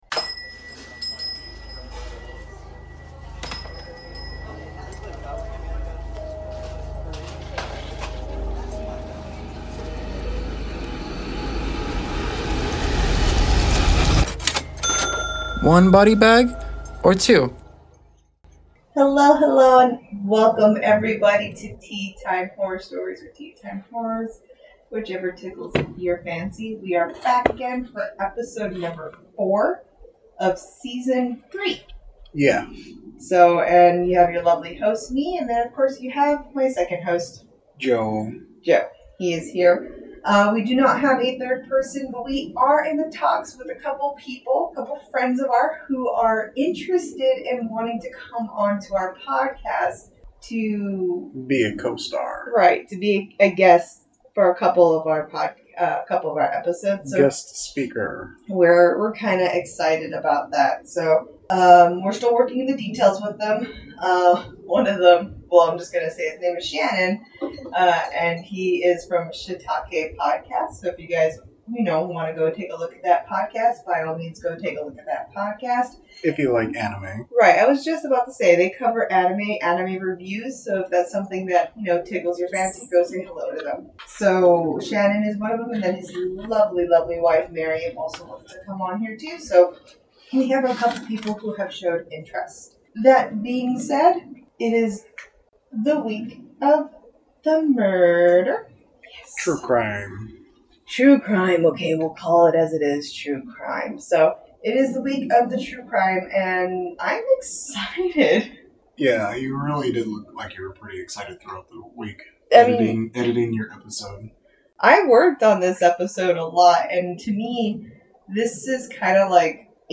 We apologize for the audio issue of this episode as we were having technical issues during the time and did not notice it until after it had been recorded.